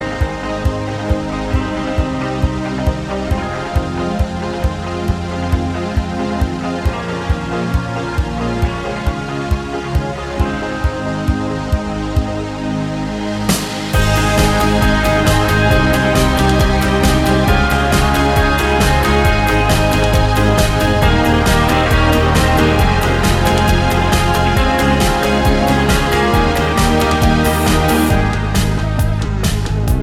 Two Semitones Down Pop